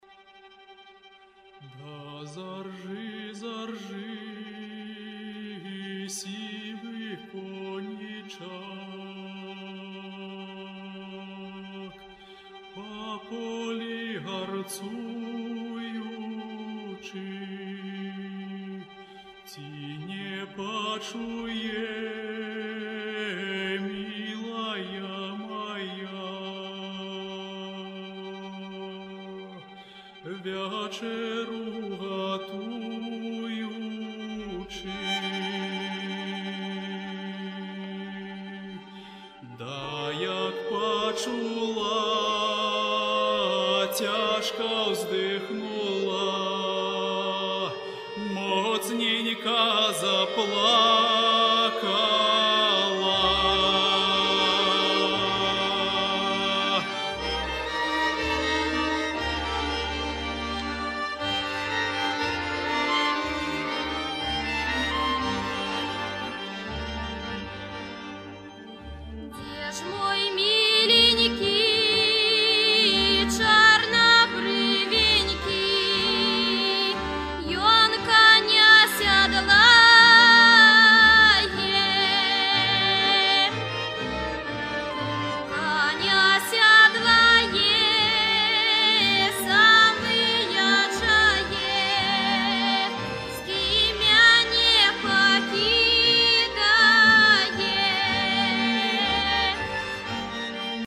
grande soirée festive de Musiques et Danses traditionnelles de Biélorussie.
culture traditionnelle dans un univers festif et flamboyant.
L'orchestre utilise plus de cent instruments originaux et chaque danse bénéficie de costumes particuliers.